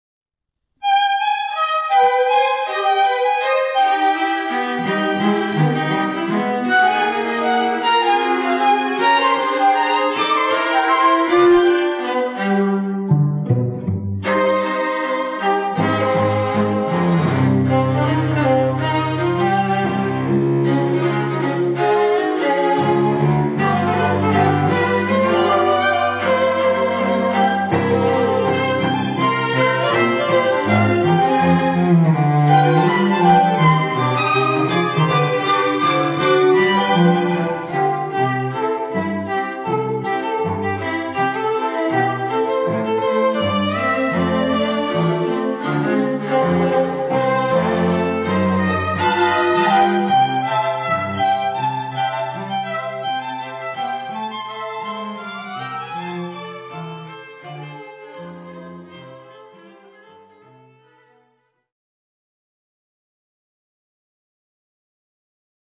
• Versatile, eclectic string quartet